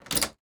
unlock-door.mp3